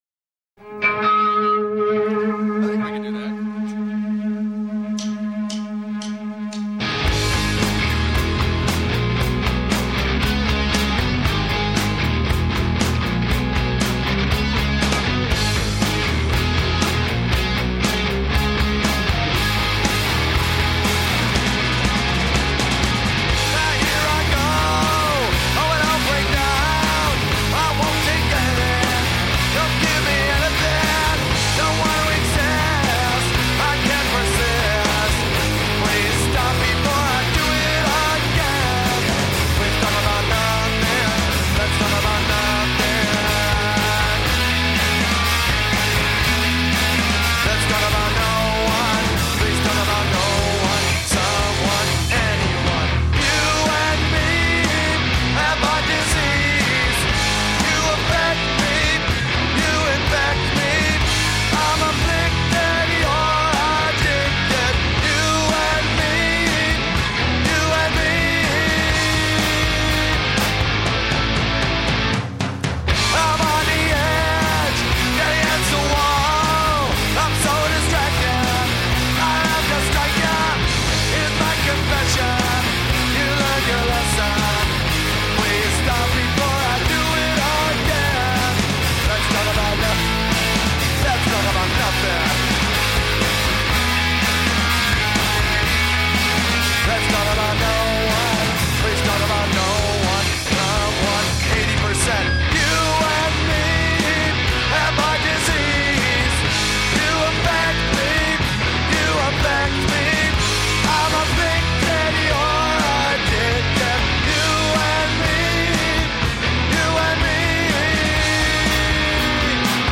Tracks 1 and 4: session on BBC Radio 1 on February 21, 1995